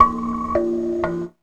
2606L KALIMB.wav